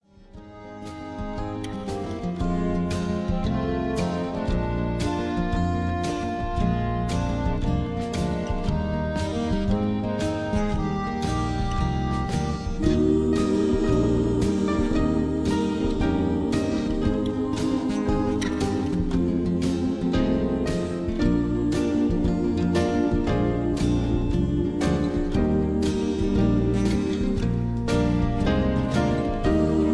(Key-E)
Tags: backing tracks , irish songs , karaoke , sound tracks